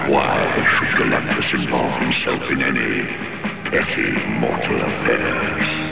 From the Fantastic Four animated series.